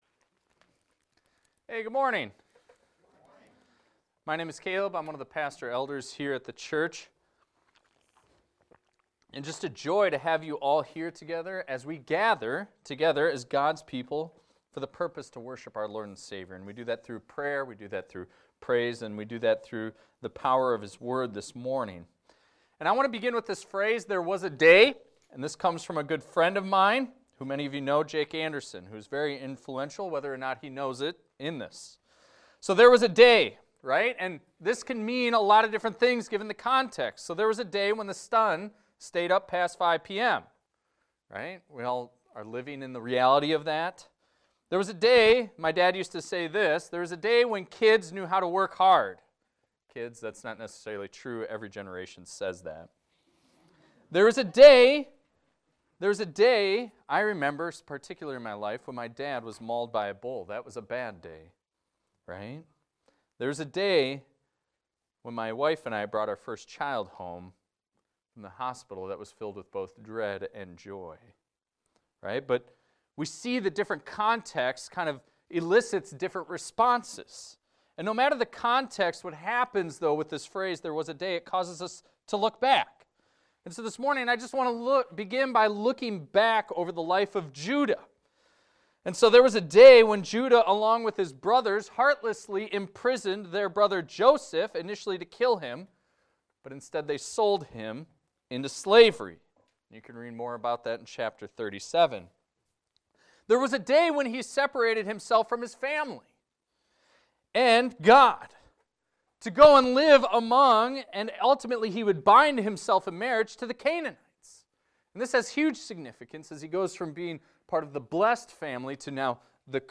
This is a recording of a sermon titled, "The Brothers Return."